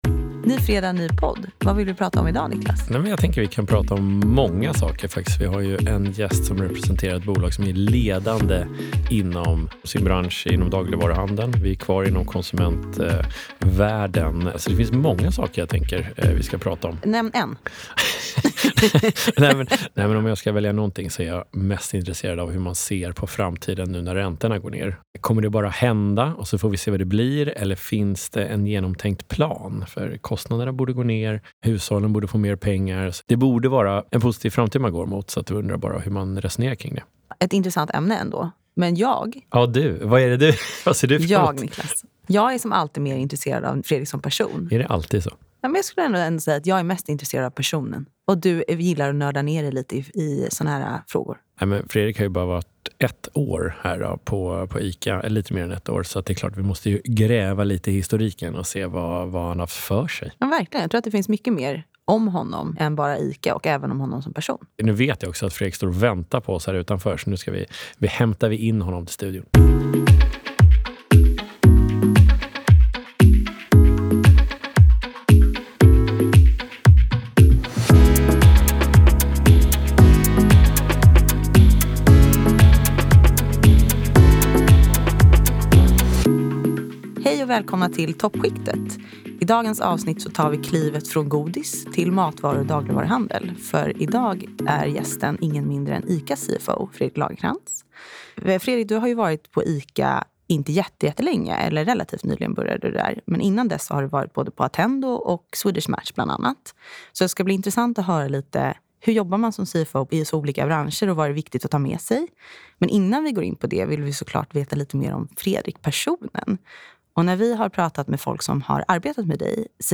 I ett hjärtligt samtal